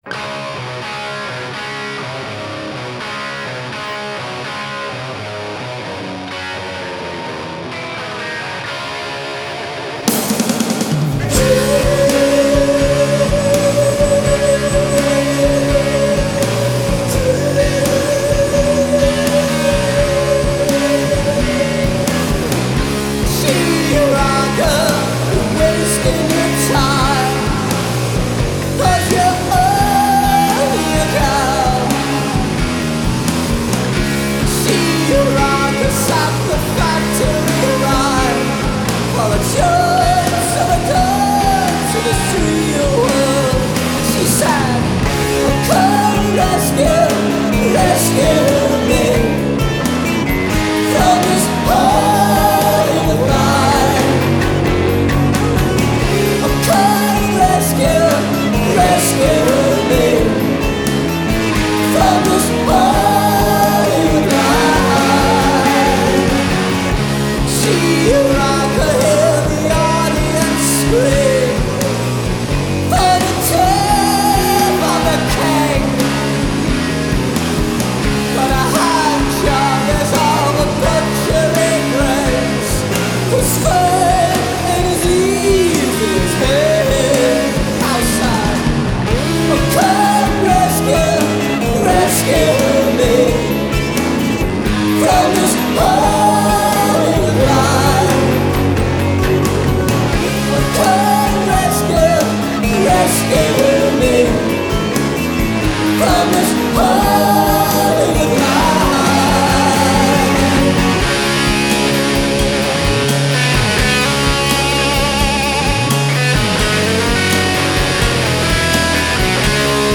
Style: Alt Rock